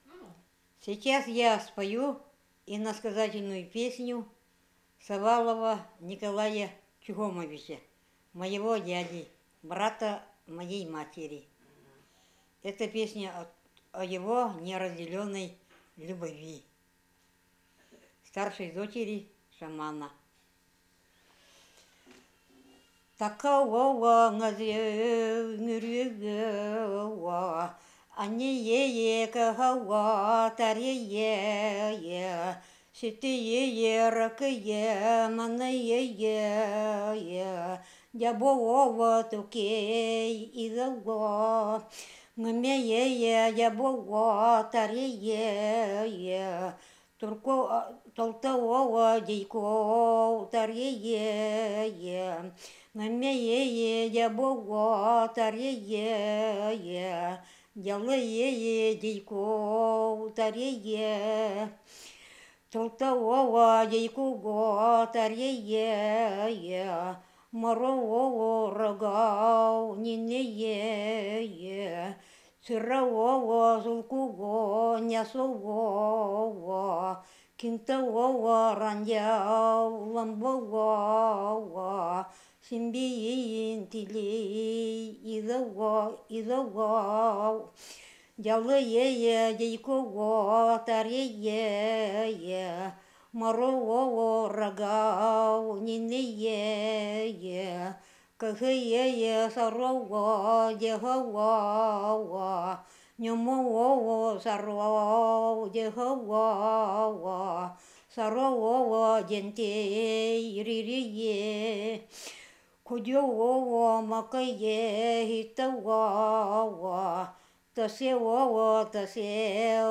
Кəиӈəирся. Иносказательная песня.